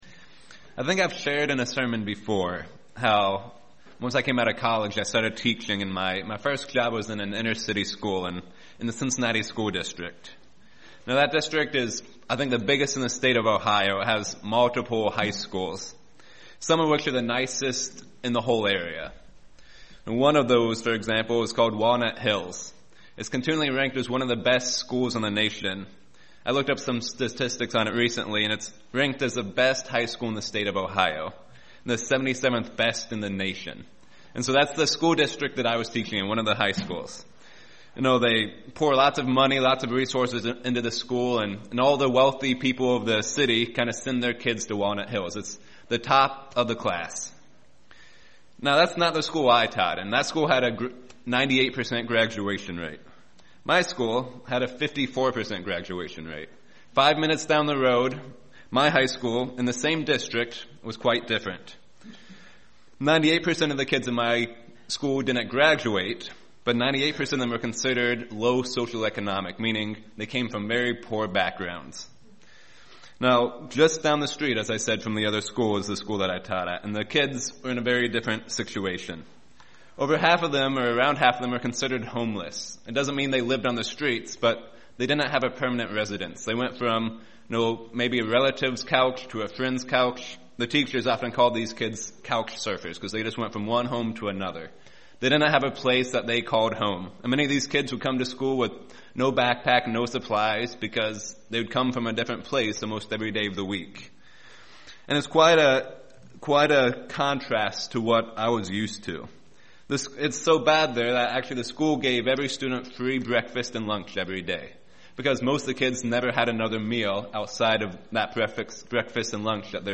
Sermons
Given in Lehigh Valley, PA Lewistown, PA York, PA